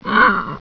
Grunt18
GRUNT18.WAV